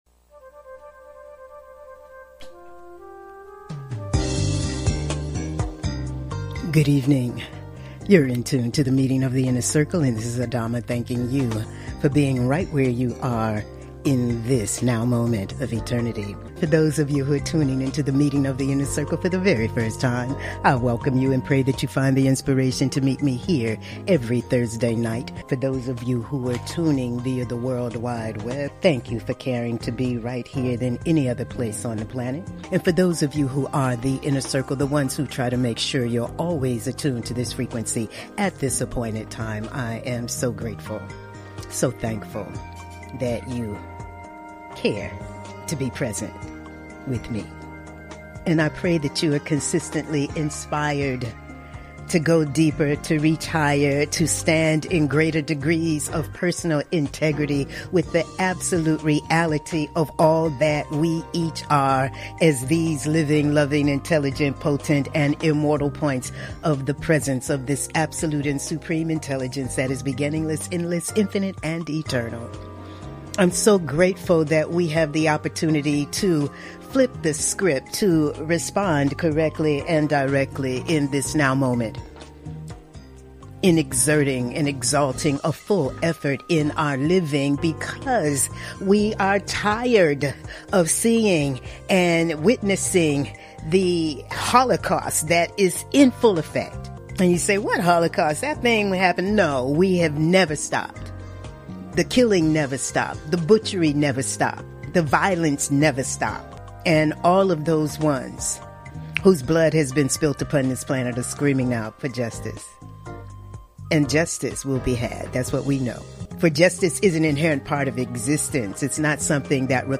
Monologues
Talk Show